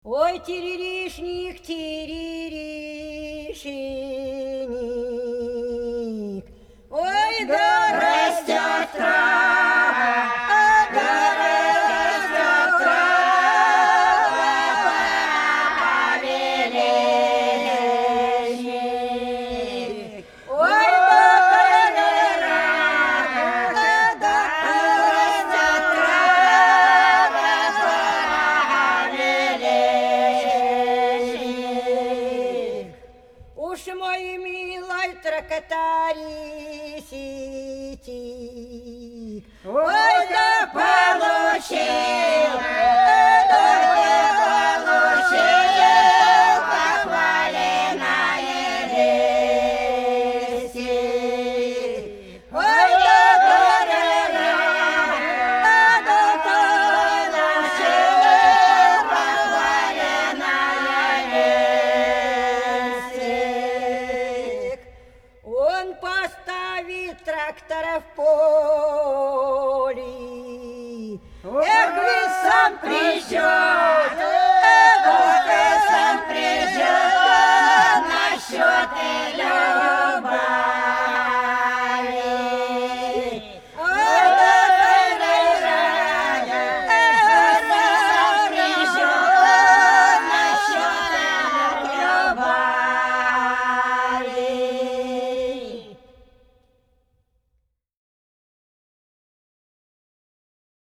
Пролетели все наши года Тириришник – страдания под язык (Фольклорный ансамбль села Пчелиновка Воронежской области)
22_Тириришник_–_страдания_под_язык.mp3